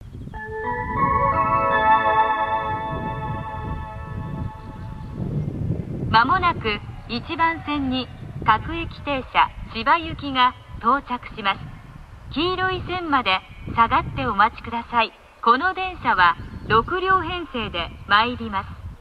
●接近放送●
浜野１番線接近放送　　各駅停車千葉行き放送です。